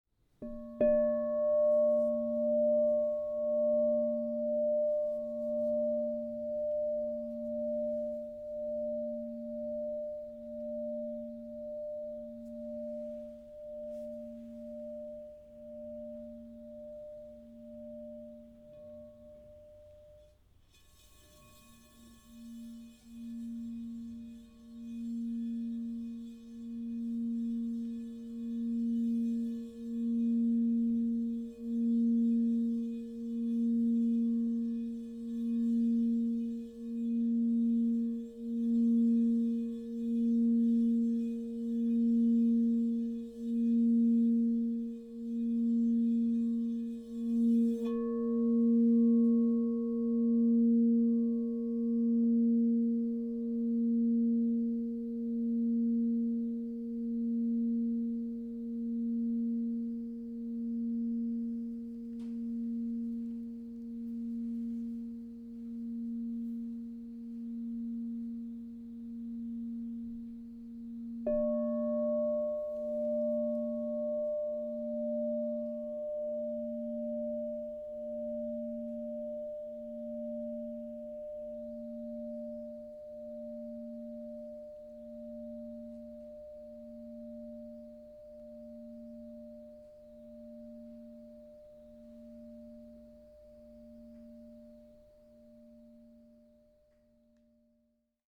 Genuine Crystal Tones® Alchemy Singing Bowl.TRUE TONE.
Libyan Gold Tektite 10″ A# 0 Crystal Tones Singing Bowl
Frequency 440Hz (TrueTone)
Note A#